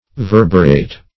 Definition of verberate.
Search Result for " verberate" : The Collaborative International Dictionary of English v.0.48: Verberate \Ver"ber*ate\, v. t. [L. verberatus, p. p. of verberare to beat, from verber a lash, a whip.]